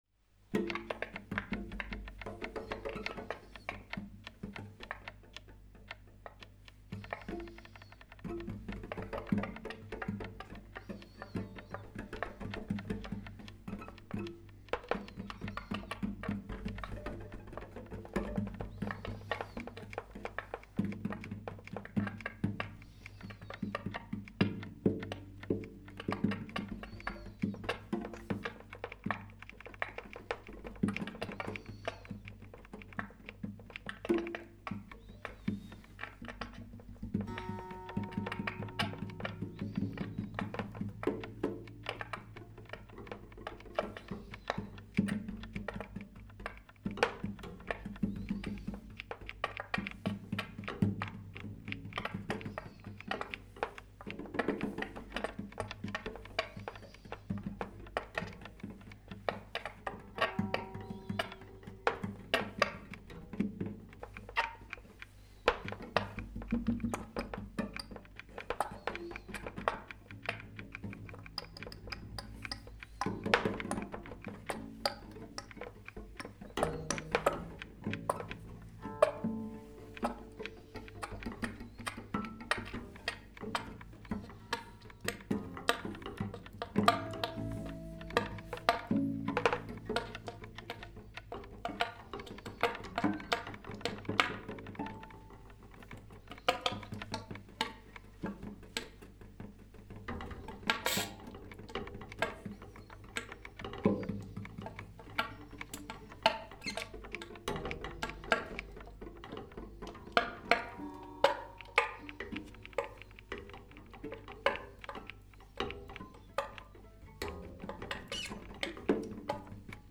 free improvised music